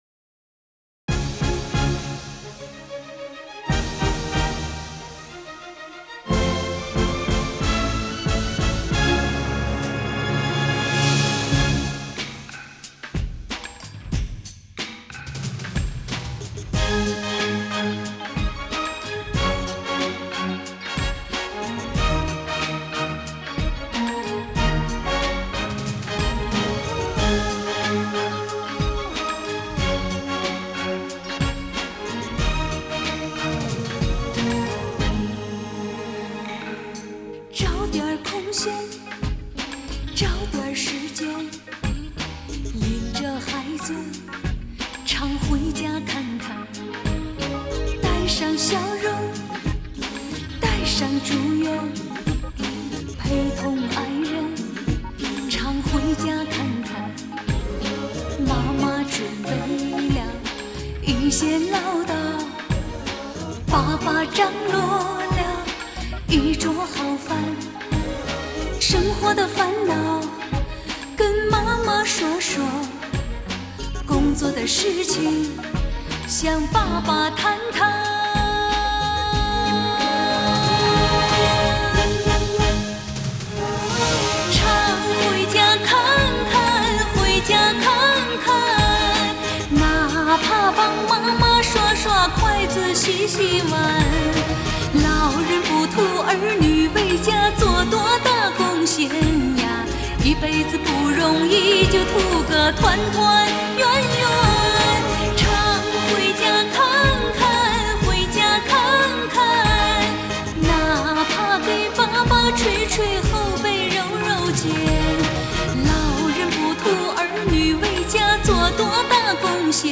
类别: 国风